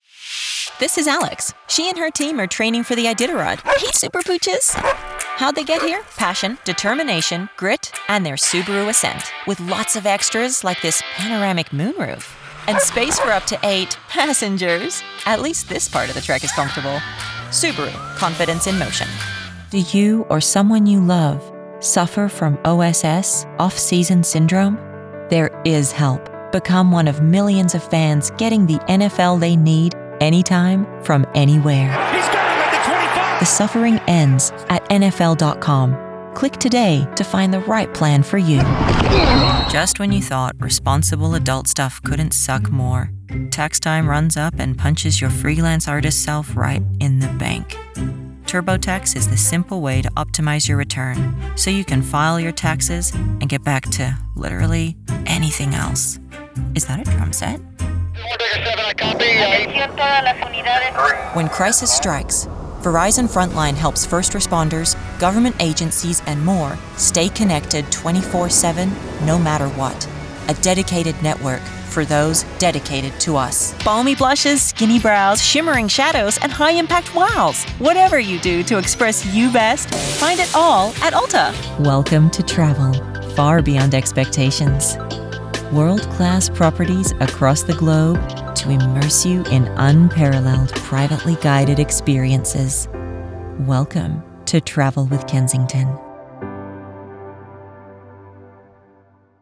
Warm, confident, nuanced, and with range from deep luxury to sarcastic milennial and everything in between.
Commercial
General American, Neutral Canadian
Young Adult
Middle Aged